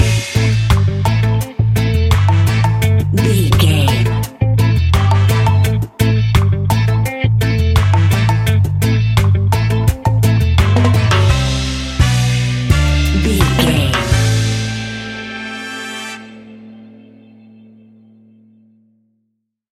Classic reggae music with that skank bounce reggae feeling.
Aeolian/Minor
dub
instrumentals
laid back
chilled
off beat
drums
skank guitar
hammond organ
transistor guitar
percussion
horns